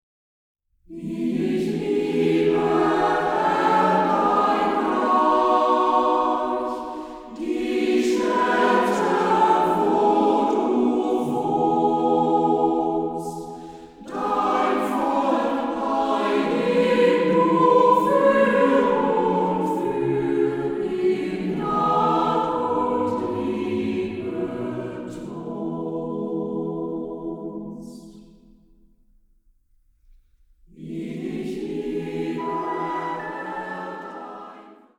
Männerchor